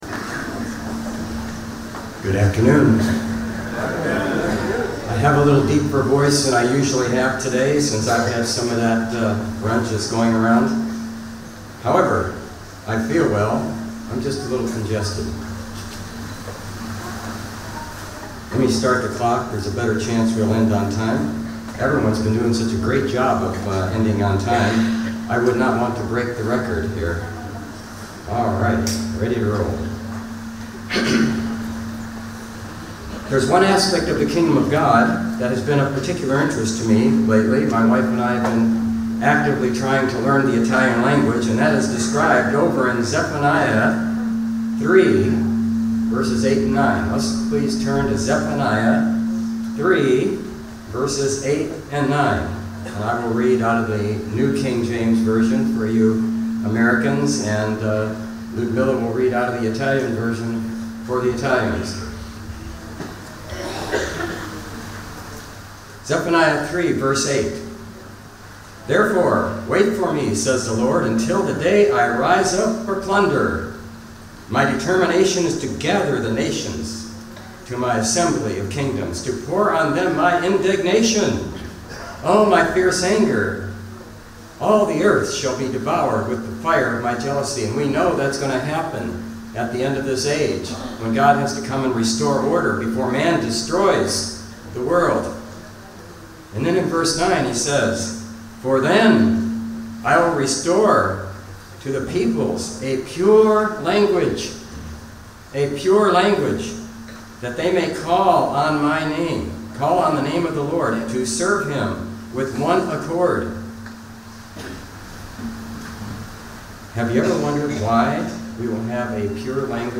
LGD 2024 Marina di Grosseto (Italy): Afternoon Service